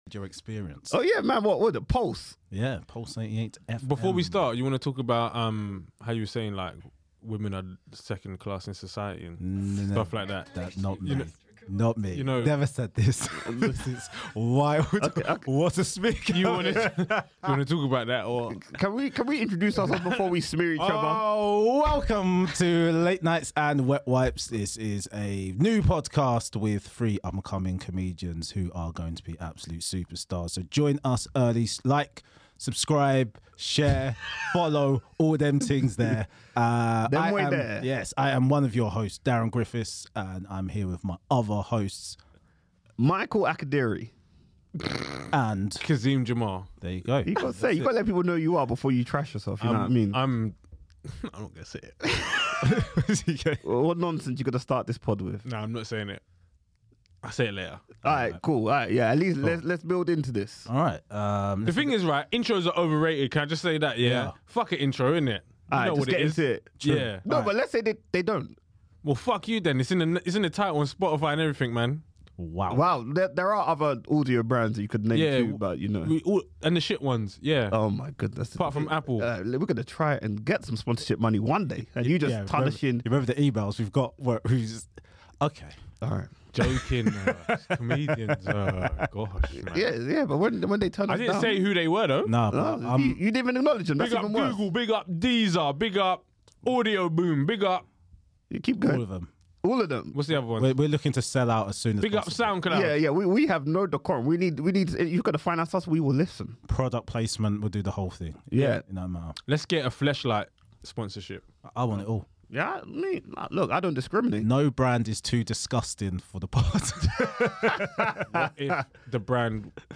The three guys discuss sharing or not sharing the load with their partners.